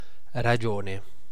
Ääntäminen
US : IPA : [ˈɹeɪ.ʃi.ˌoʊ]